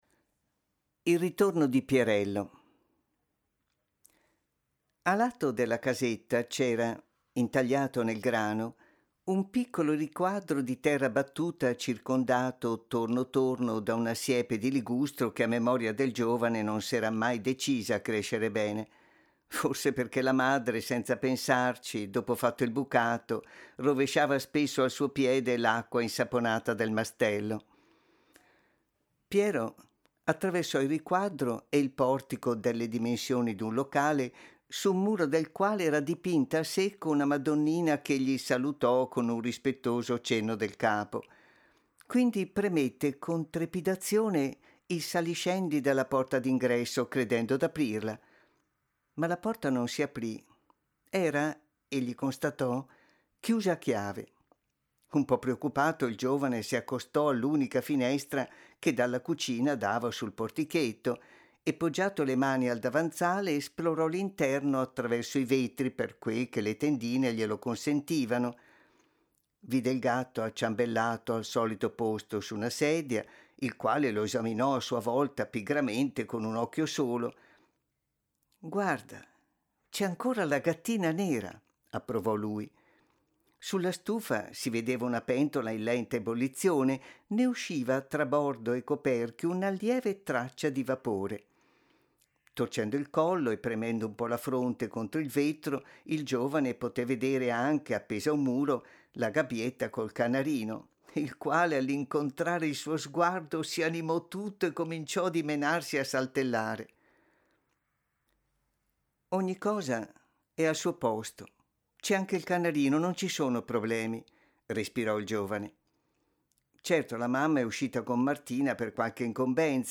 Proponiamo la terza delle letture tratte dalle opere di Eugenio Corti che i visitatori delle giornate di primavera del FAI hanno potuto ascoltare il 25 e il 26 marzo scorsi in occasione della visita a villa Corti; anche questo brano è tratto da Il cavallo rosso.